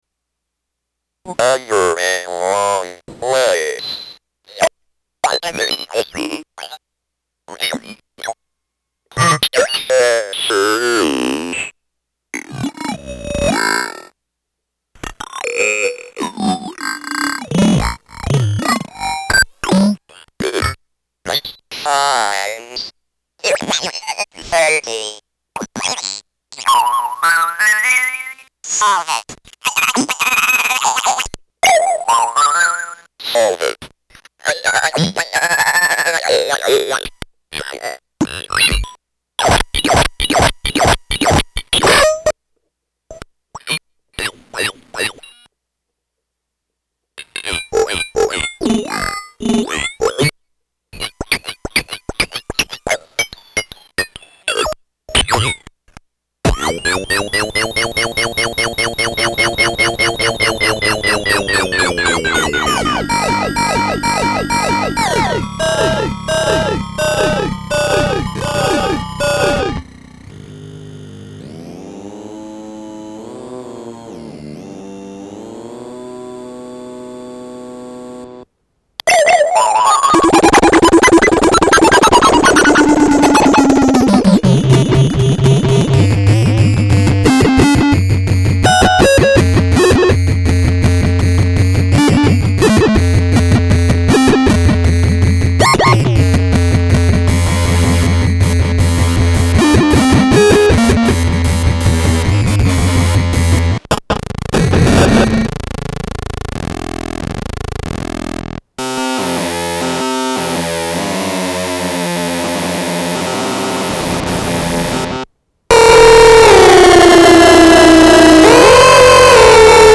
You are looking at a carefully modified Circuit Bent Speak & Math, ready for you to glitch and tweak at your next gig or in your studio.
Listen for my use of a delay pedal in the sample.
With a little persuasion, this thing will jabber nonsense interspersed with tones and whole words.
This thing sounds great through an effects or delay pedal!